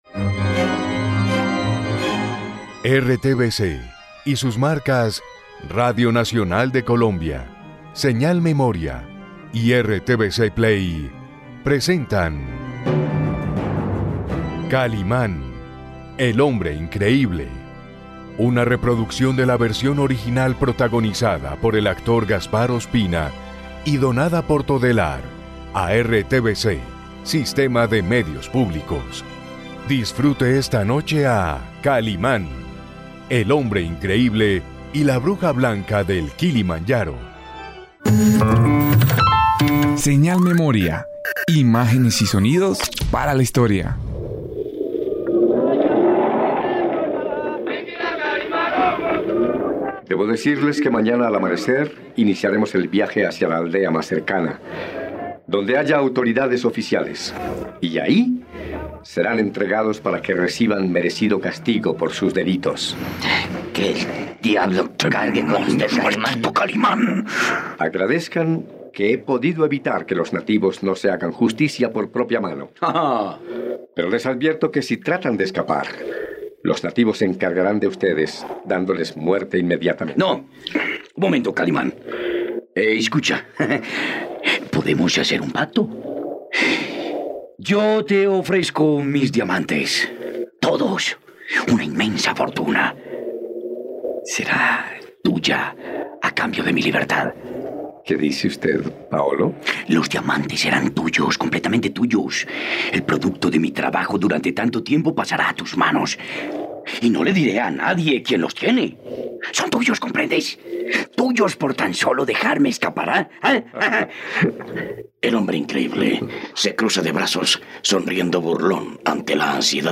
No te pierdas todos los capítulos de esta radionovela por RTVCPlay.